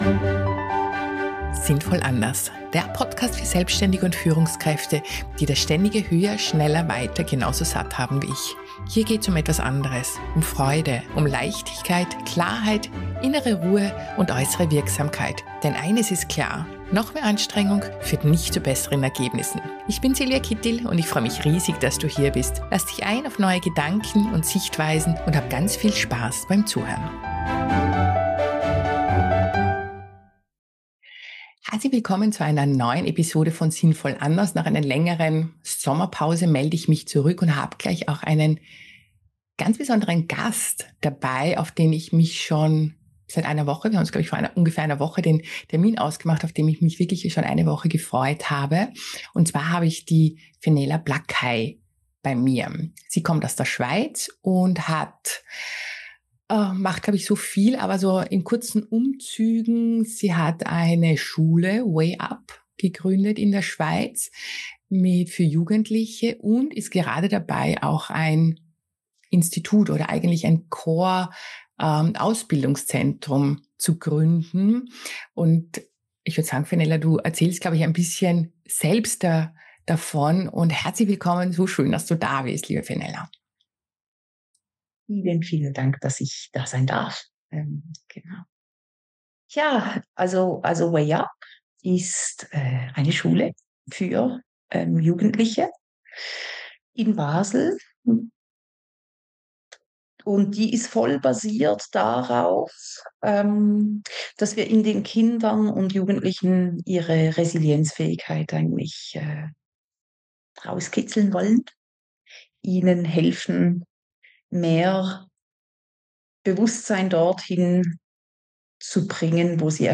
Ein ehrliches Gespräch über das Menschsein, innere Ruhe und die Frage, wie wir alle besser miteinander umgehen können.